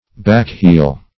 Backheel \Back"heel`\, n.